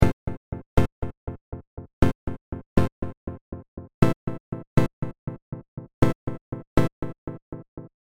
bist du dir sicher? die echos muessen immer dumpfer werden, wenn man einen highcut einstellt, ist hier nicht der fall